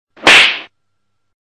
Slap 101